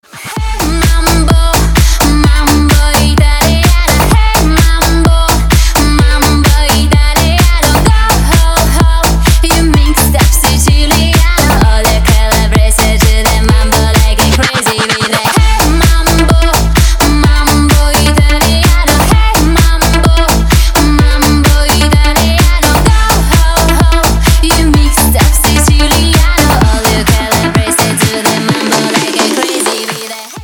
Нарезки энергичных ремиксов
• Категория: Рингтоны